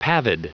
Prononciation du mot pavid en anglais (fichier audio)
Prononciation du mot : pavid